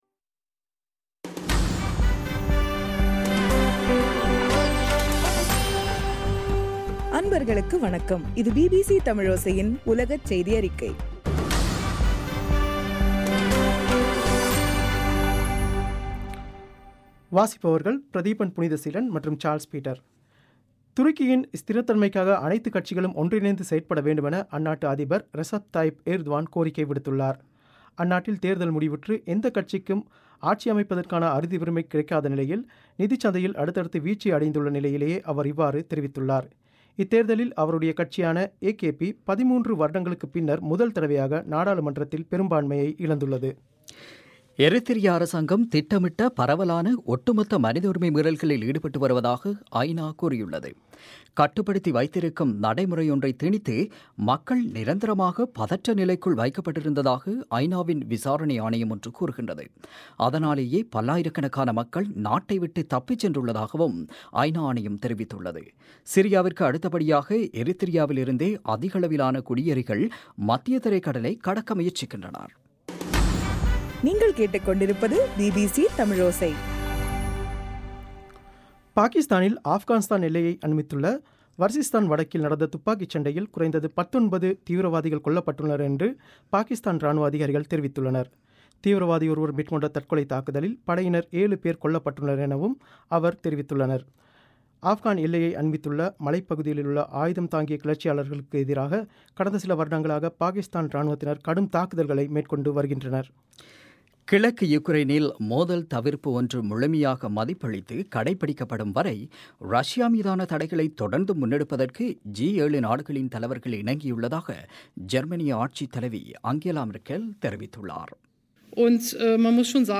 ஜூன் 8 2015 பிபிசி தமிழோசையின் உலகச் செய்திகள்